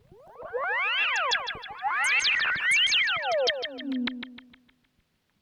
Bird Fight.wav